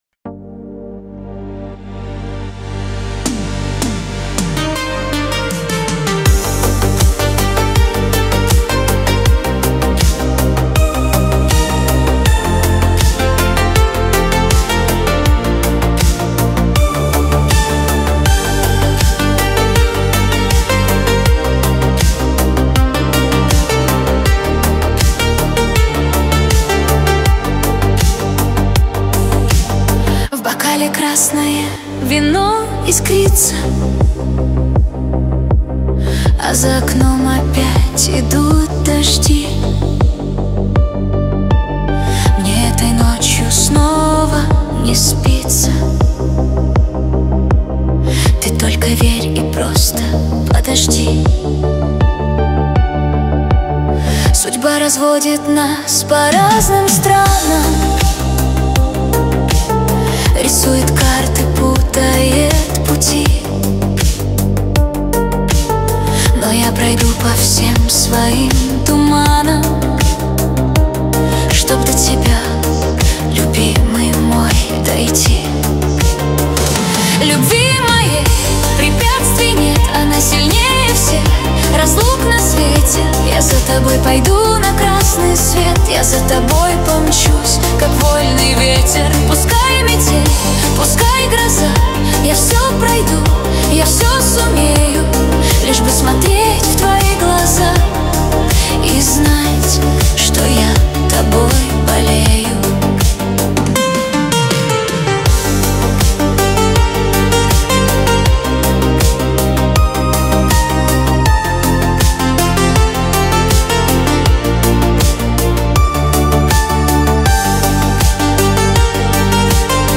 15 декабрь 2025 Русская AI музыка 123 прослушиваний